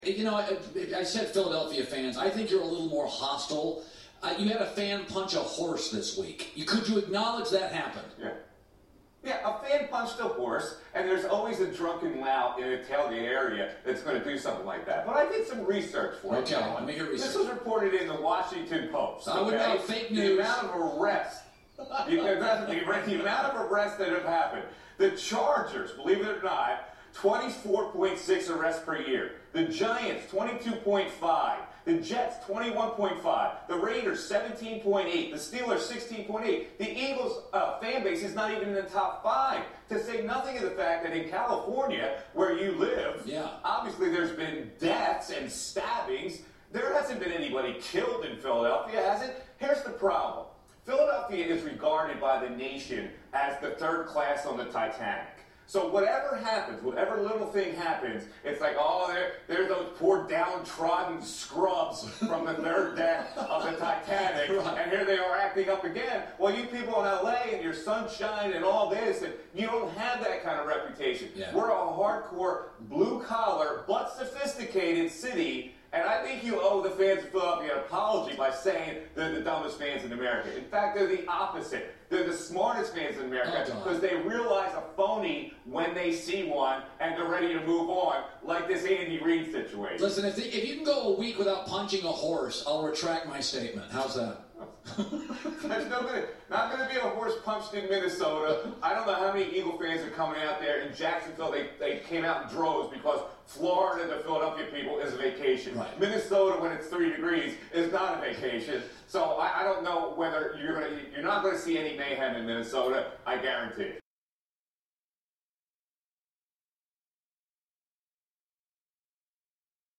It was not a combative segment. It really wasn’t. It was jovial and the jabs were friendly.
It was less of a RADIO WAR and more of a radio skirmirsh.